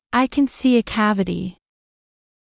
ｱｲ ｷｬﾝ ｼｰ ｱ ｷｬﾋﾞﾃｨ